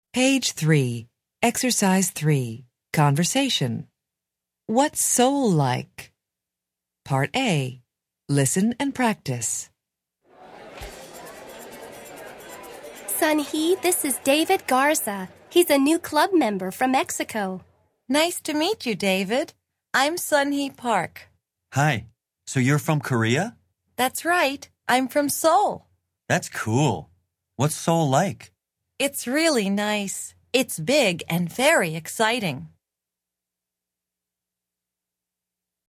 Interchange Third Edition Level 1 Unit 1 Ex 3 Conversation Track 2 Students Book Student Arcade Self Study Audio
interchange3-level1-unit1-ex3-conversation-track2-students-book-student-arcade-self-study-audio.mp3